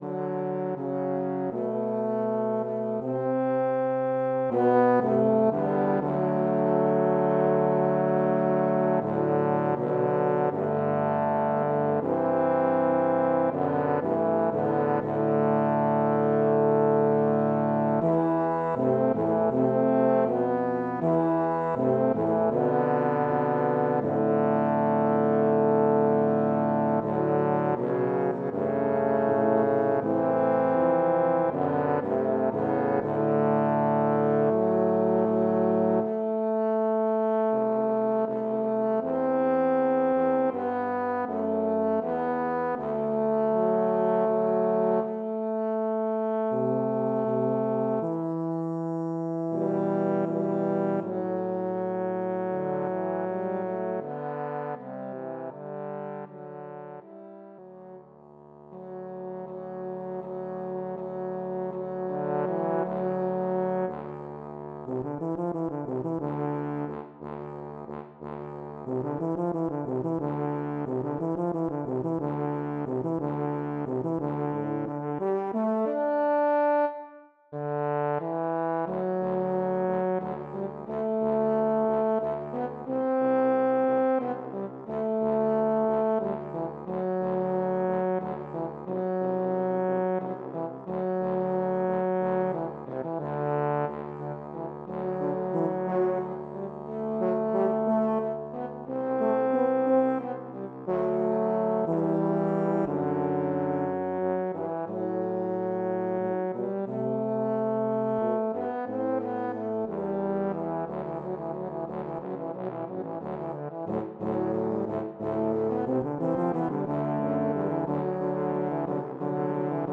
Voicing: Tuba Quartet (EETT)